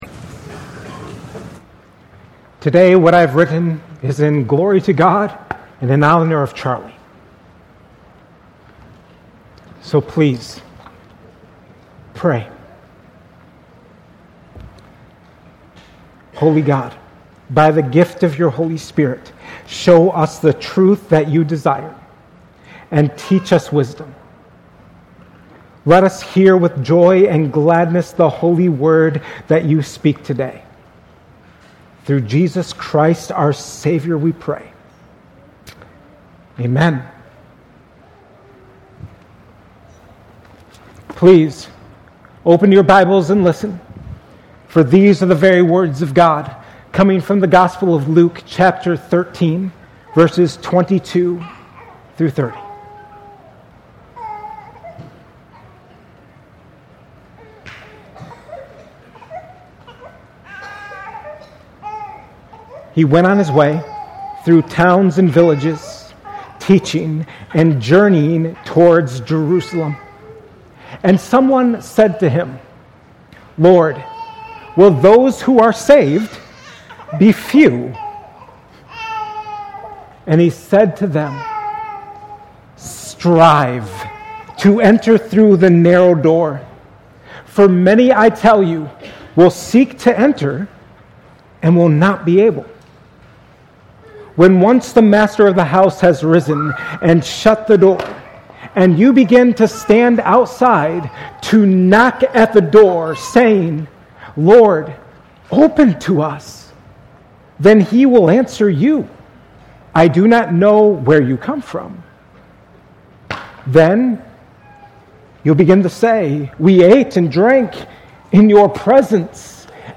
2025 at Cornerstone Church.